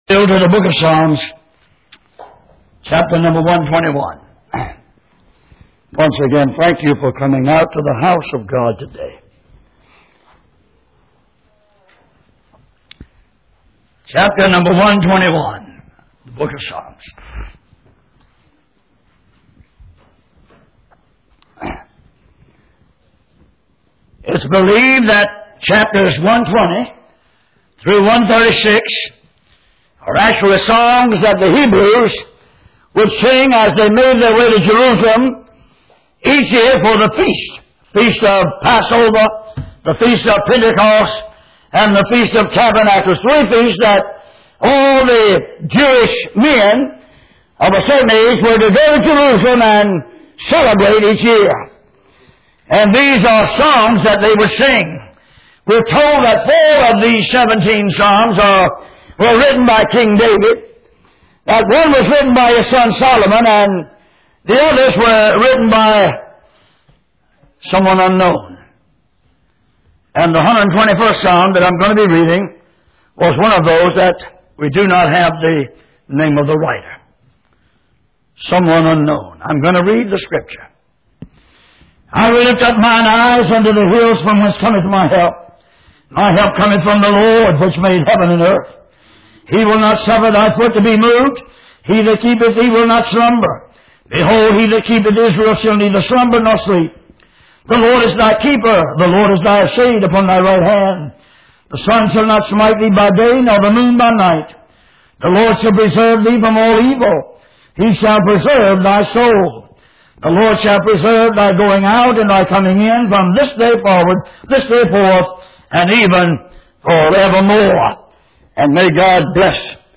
Passage: Psalm 121:1-2 Service Type: Sunday Morning